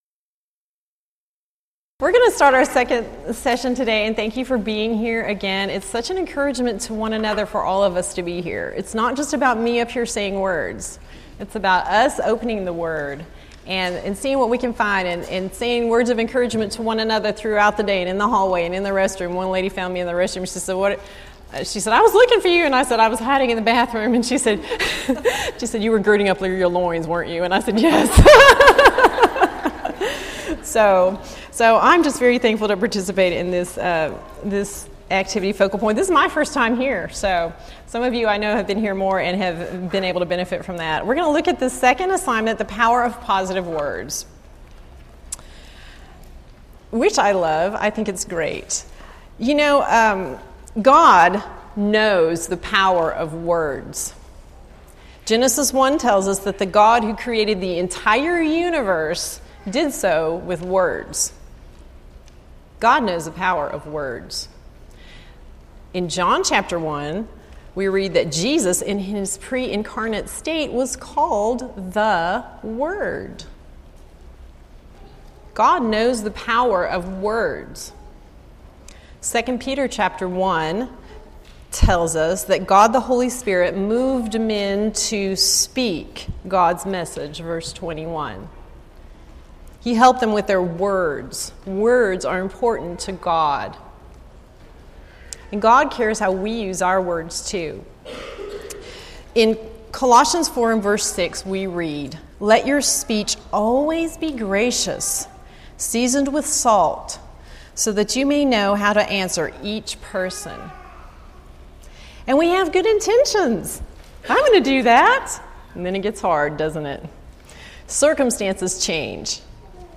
Event: 2016 Focal Point Theme/Title: Preacher's Workshop
Ladies Sessions , Speech